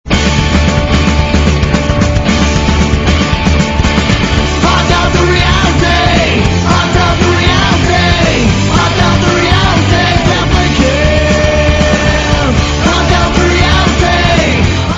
punk-rock